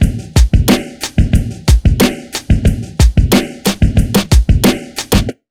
Index of /90_sSampleCDs/USB Soundscan vol.02 - Underground Hip Hop [AKAI] 1CD/Partition A/11-91BEATMIX